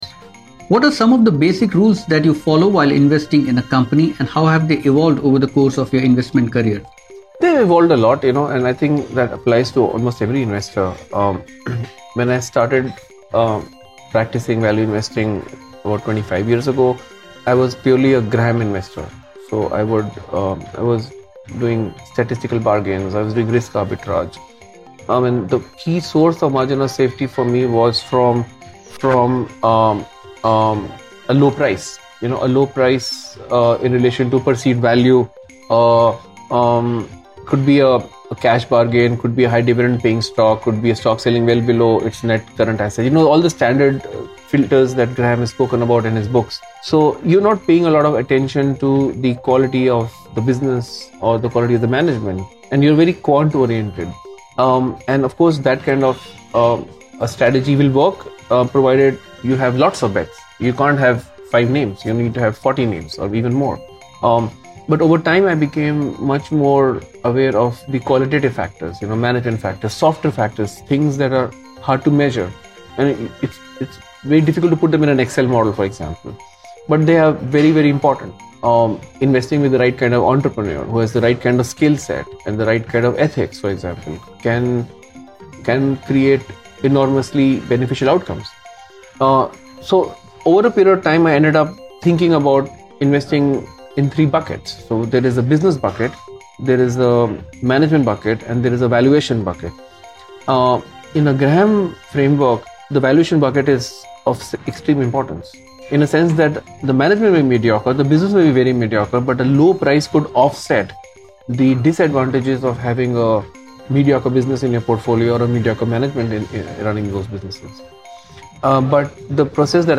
In part 3 of a podcast interview